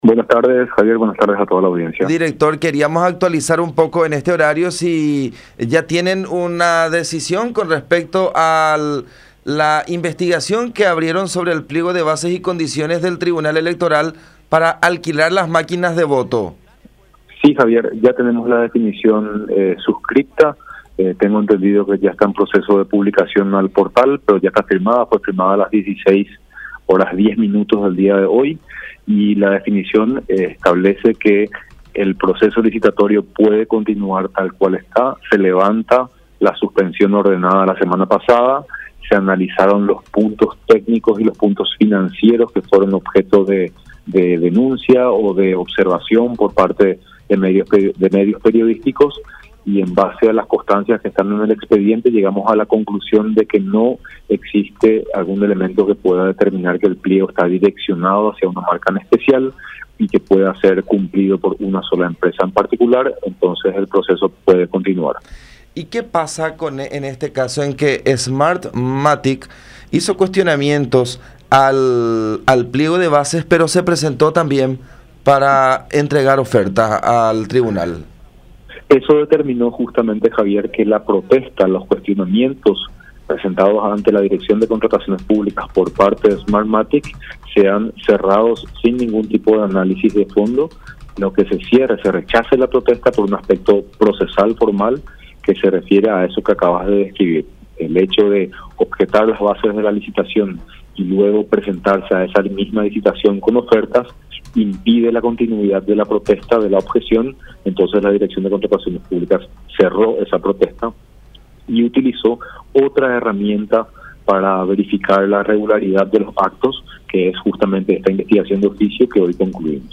Se levanta la suspensión ordenada la semana pasada, luego de ser analizados los puntos técnicos y financieros que fueron objetos de denuncias y de observación, mencionó Pablo Seitz, Director de Contrataciones Públicas en comunicación con La Unión R800 AM